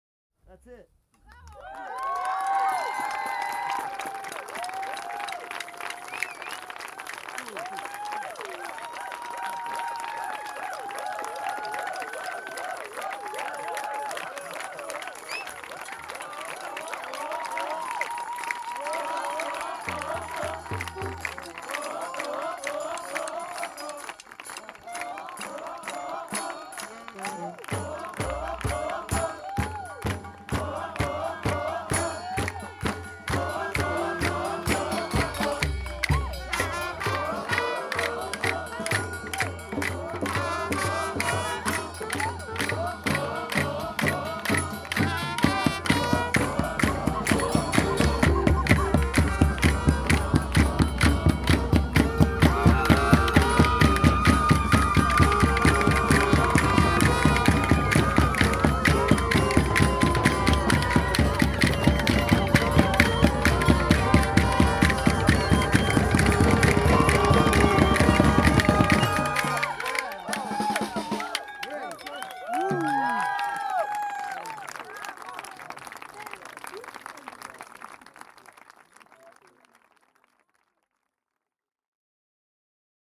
Genre: Experimental